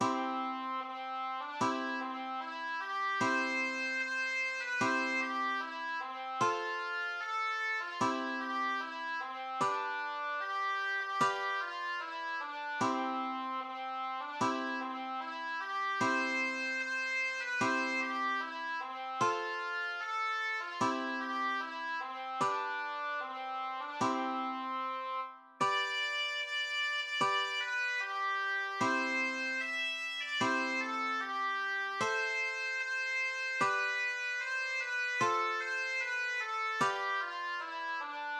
Marschlied einer Söldnertruppe